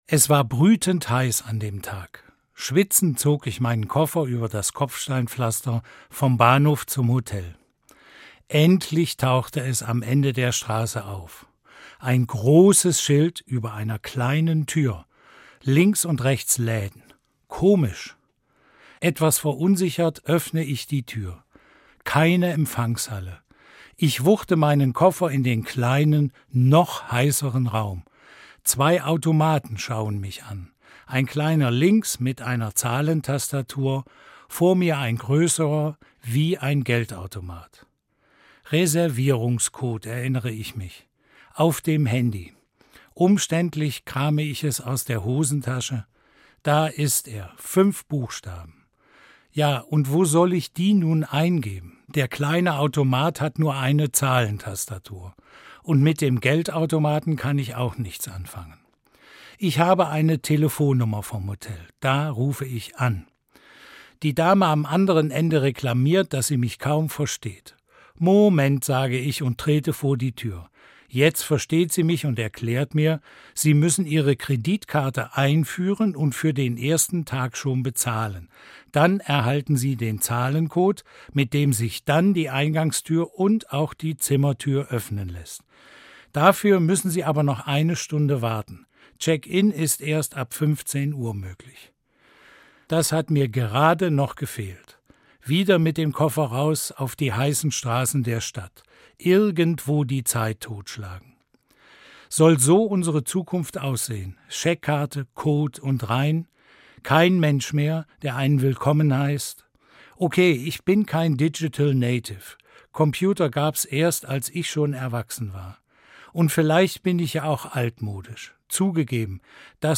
Evangelischer Pfarrer i. R., Kassel